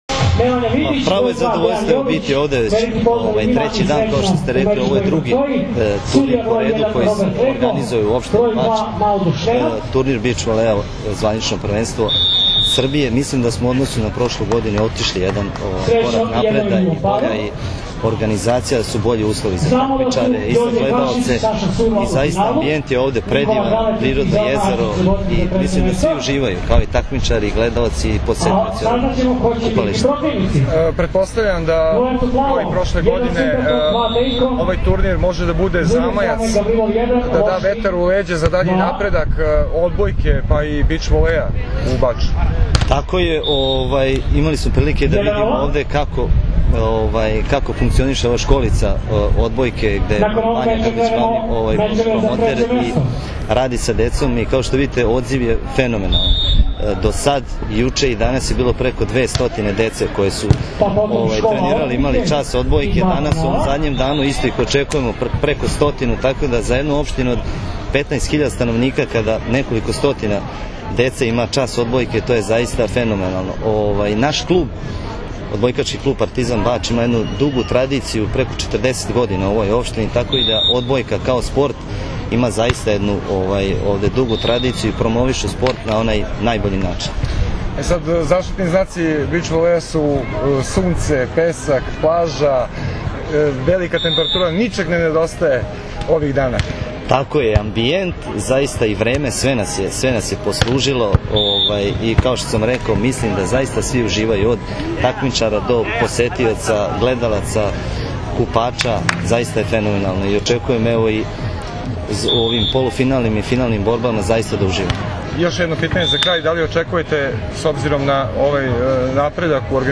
IZJAVA OGNJENA MARKOVIĆA, PREDSEDNIKA OPŠTINE BAČ